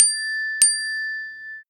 glass.mp3